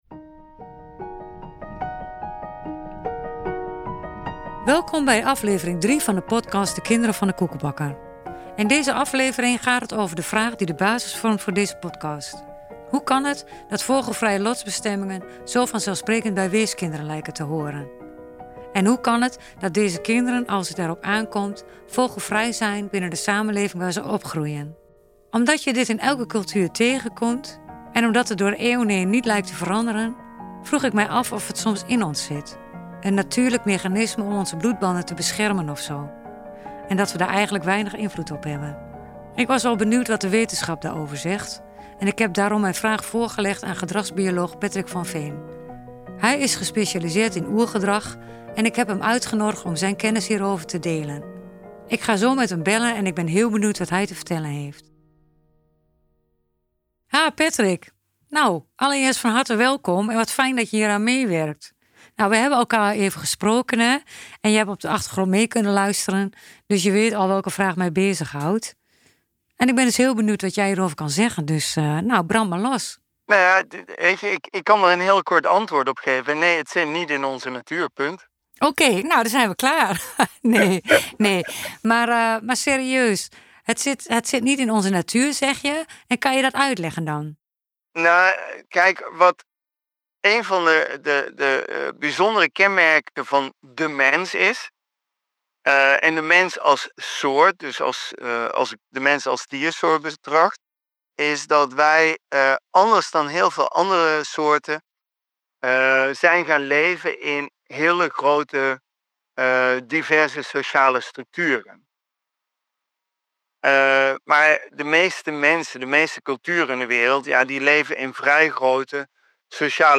Een boeiend gesprek over natuur versus cultuur, overlevingsmechanismen, anonimiteit en classificatiesystemen.